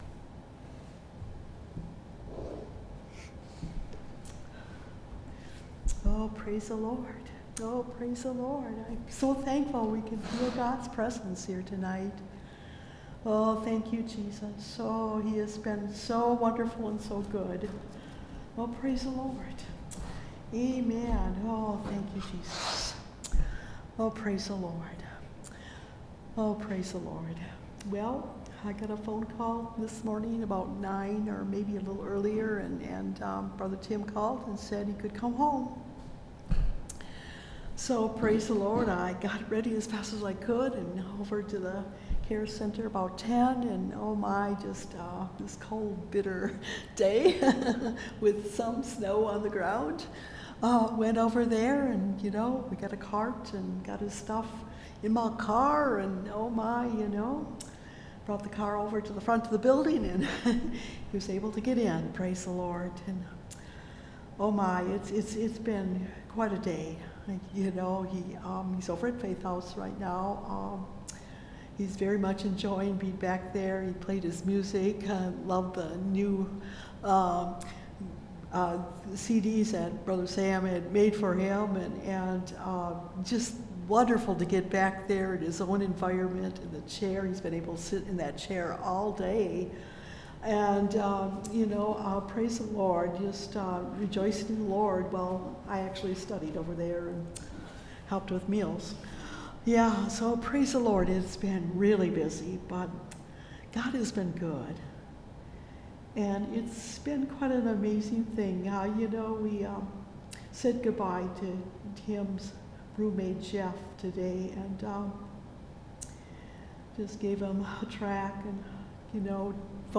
O Give Thanks Unto The Lord (Message Audio) – Last Trumpet Ministries – Truth Tabernacle – Sermon Library